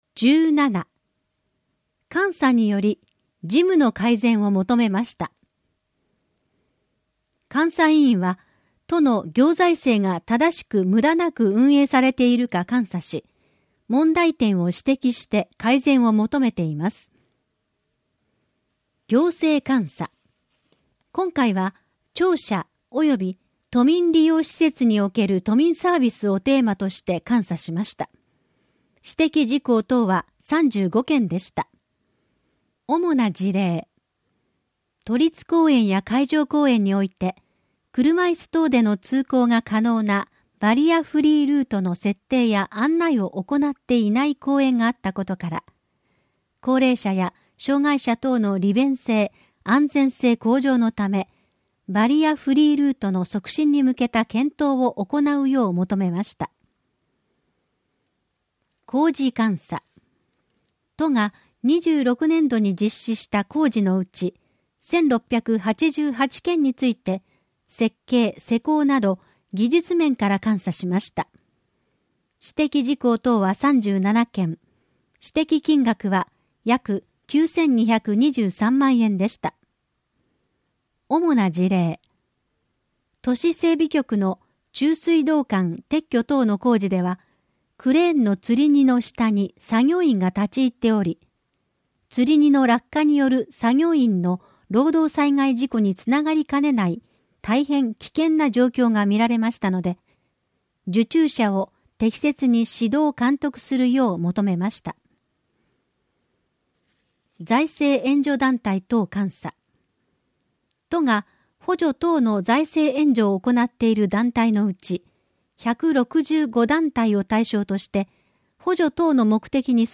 「広報東京都 音声版」は、視覚に障害のある方を対象に「広報東京都」の記事を再編集し、音声にしたものです。